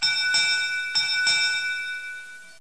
bell.mp3